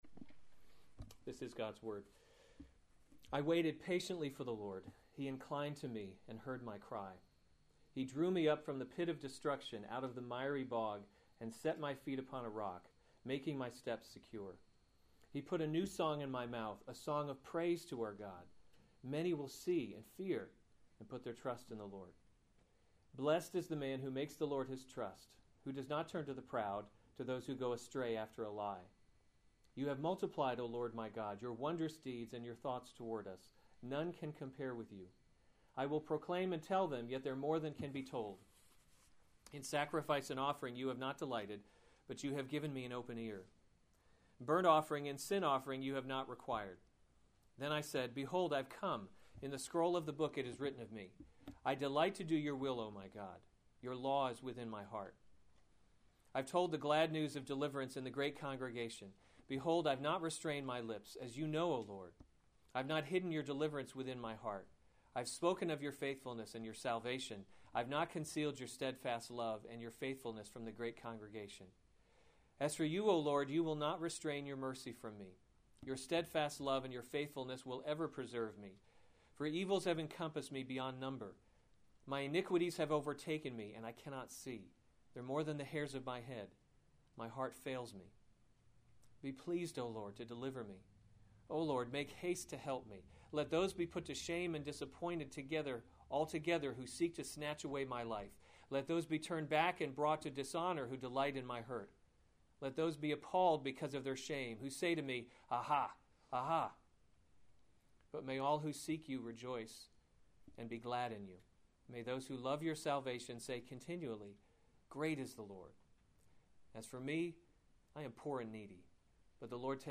August 29, 2015 Psalms – Summer Series series Weekly Sunday Service Save/Download this sermon Psalm 40 Other sermons from Psalm My Help and My Deliverer To the choirmaster.